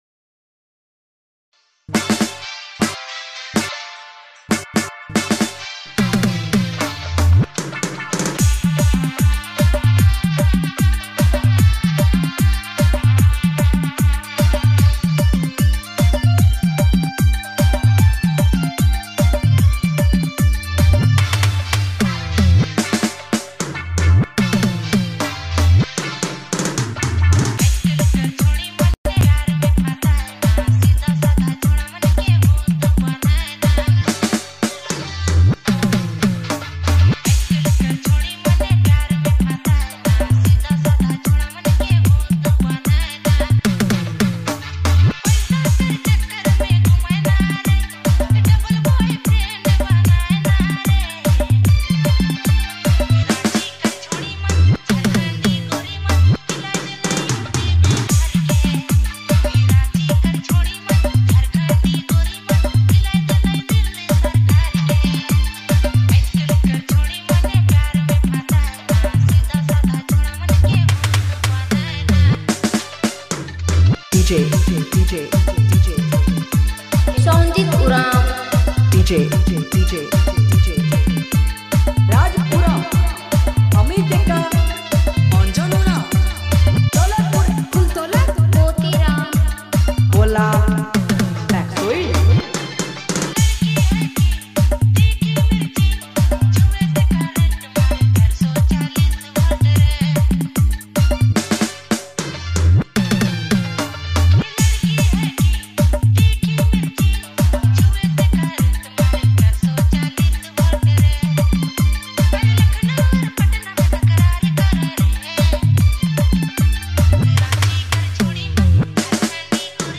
Dj Remixer